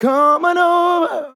COME ON OVER Vocal Sample
Categories: Vocals
man-disco-vocal-fills-120BPM-Fm-16.wav